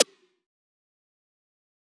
SizzSnr3.wav